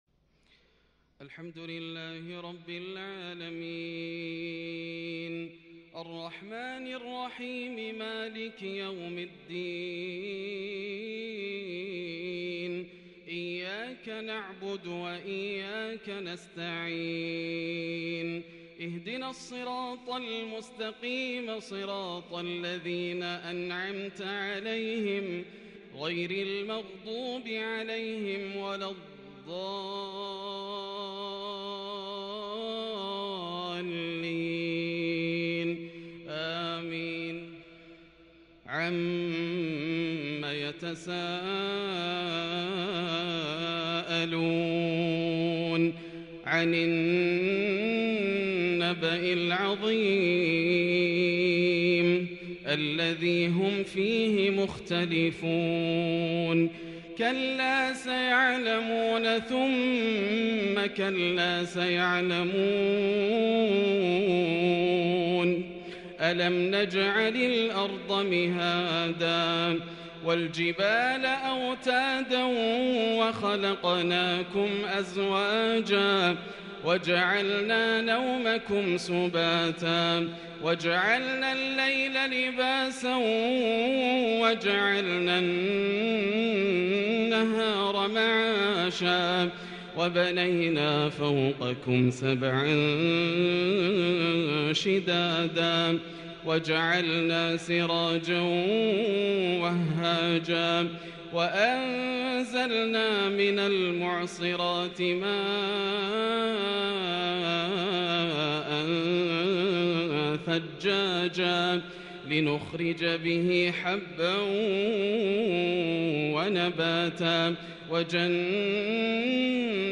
سورتي النبأ والأعلى | Isha prayer from Surah Annaba and Al-a’ala | 10/4/2021 > 1442 🕋 > الفروض - تلاوات الحرمين